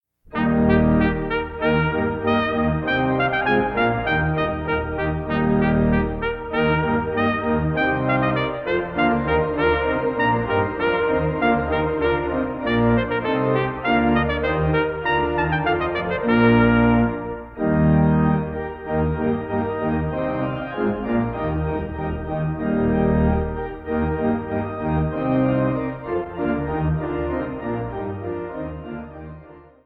Trumpet
Organ